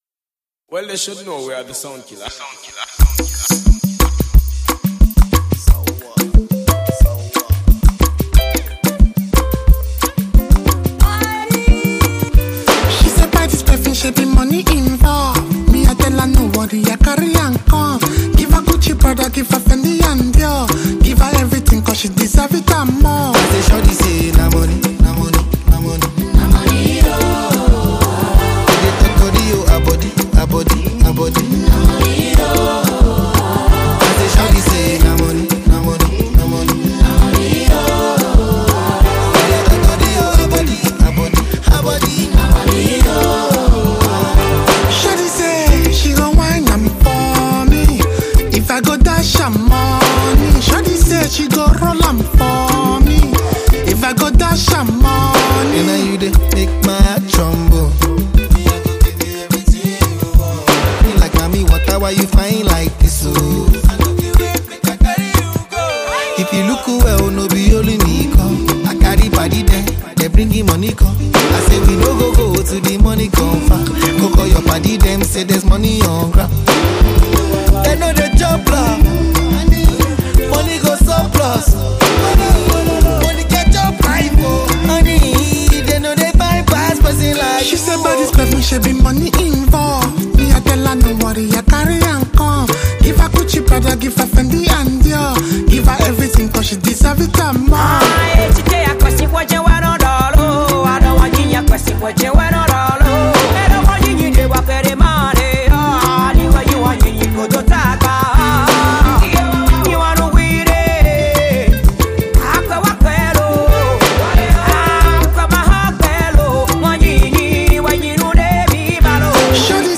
The album mixes Afrobeats, amapiano, and highlife sounds.